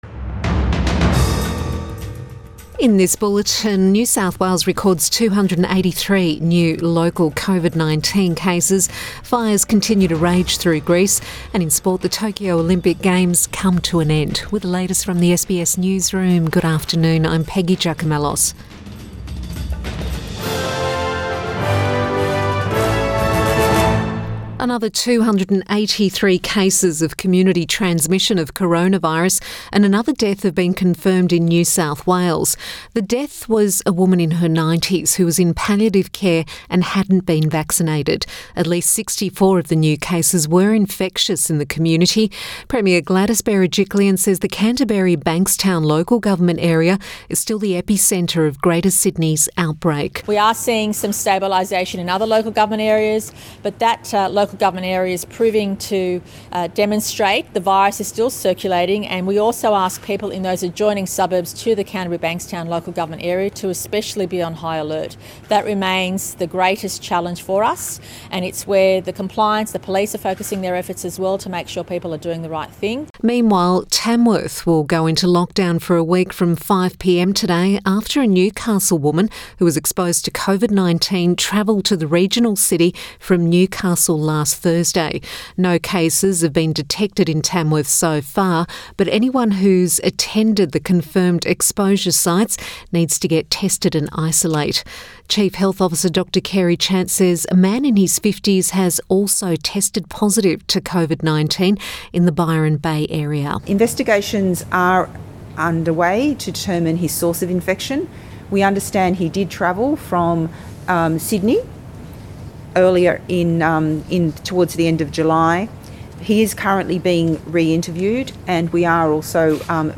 Midday bulletin 9 August 2021